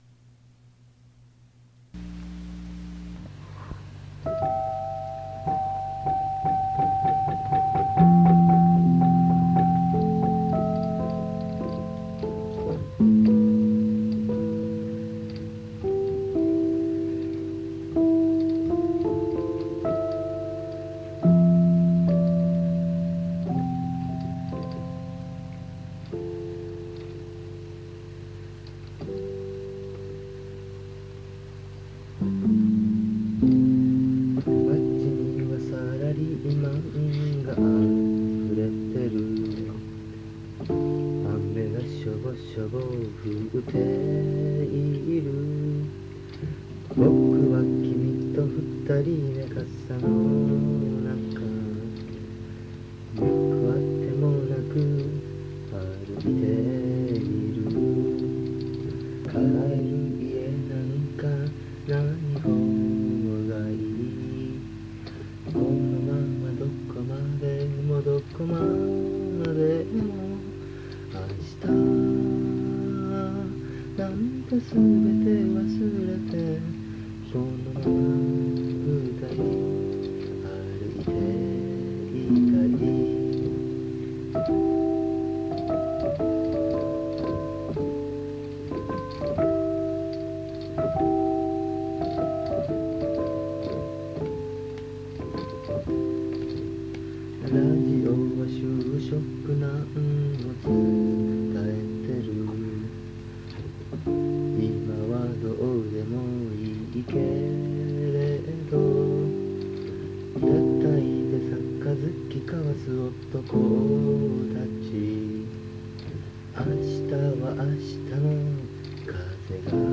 その当時のエレピアンの演奏を録音したものを
ラジカセで録音した物です。
当時のエレピアン特有の、鍵盤を押した時の音「コツコツ」と云う音が入っています。